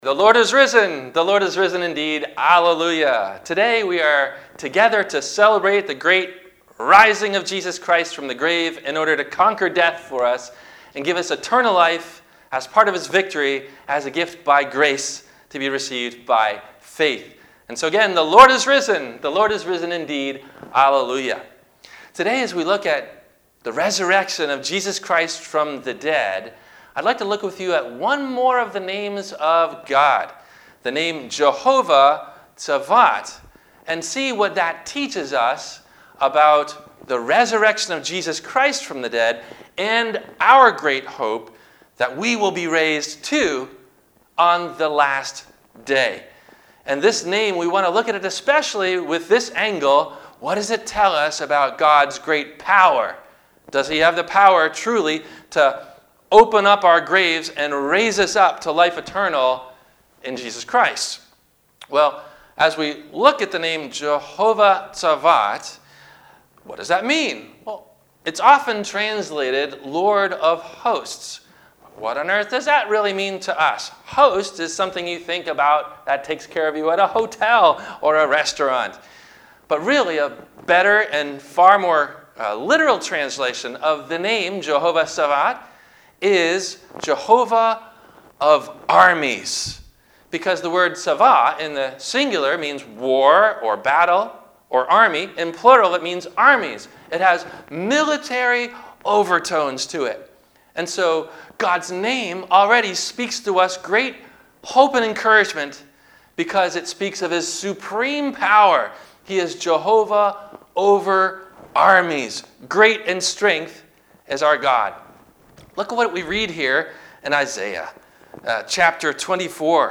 What Does It Mean That God Is - "JEHOVAH TSAVOT"? - Easter - ENTIRE CHURCH SERVICE - Corona 4 - April 12 2020 - Christ Lutheran Cape Canaveral
NOTE: DUE TO THE CORONA V QUARANTINE, THE VIDEO BELOW IS THE ENTIRE CHURCH SERVICE AND NOT JUST THE SERMON AS IN THE PAST.